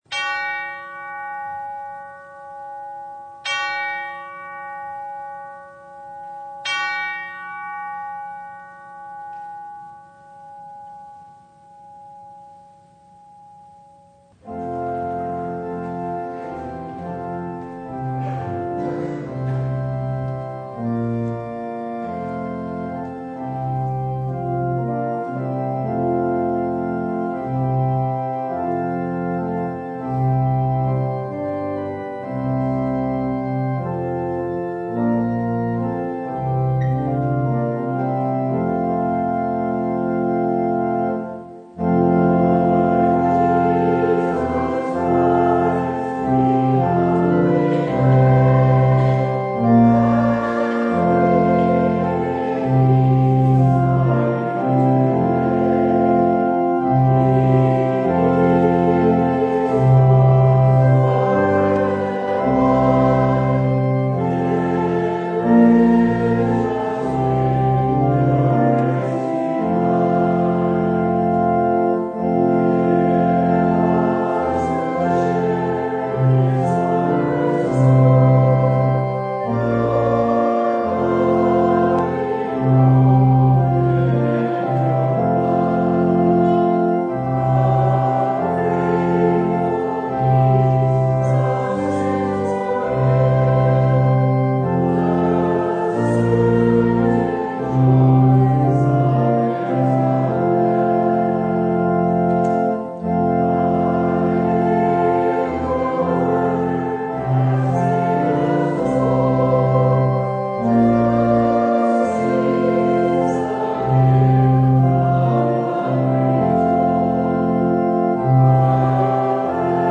Passage: Matthew 10:38-42 Service Type: Sunday
Full Service